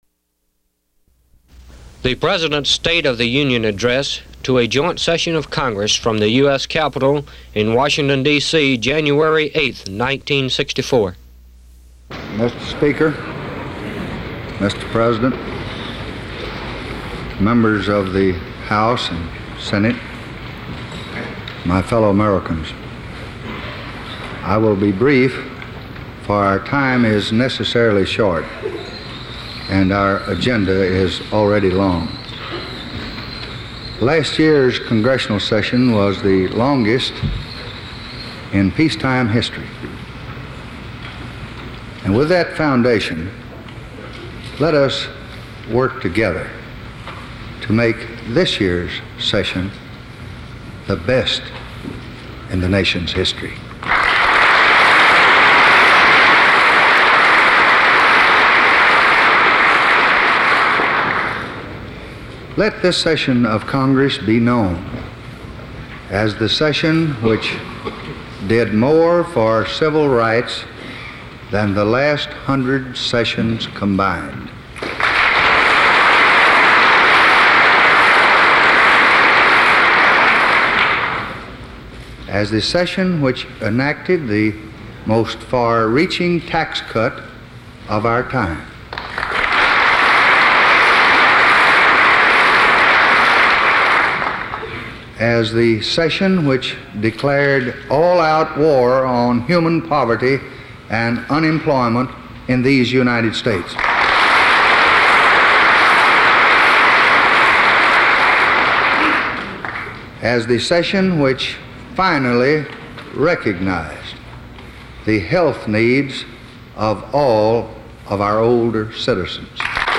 Lyndon Baines Johnson: First State of the Union Address by Lyndon Johnson on Free Audio Download
LyndonJohnsonFirstStateoftheUnionAddress.mp3